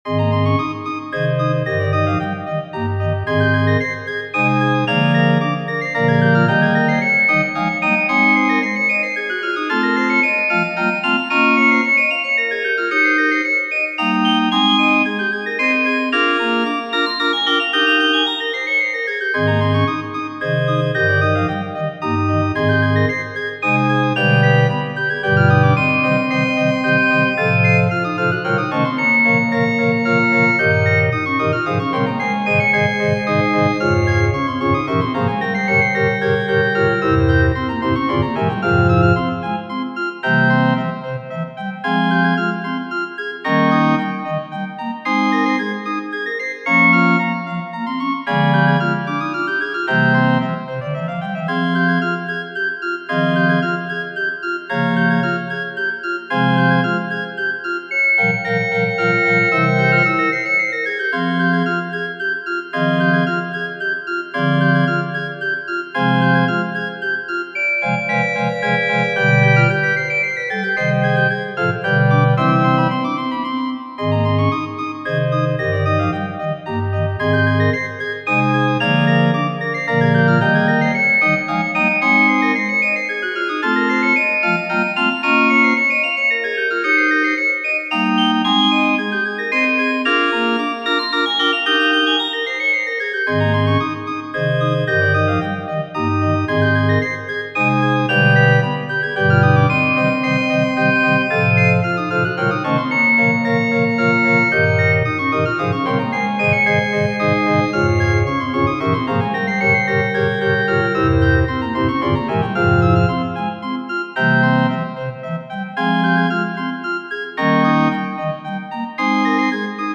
prelude and fugue in a flat major - Piano Music, Solo Keyboard - Young Composers Music Forum
prelude and fugue in a flat major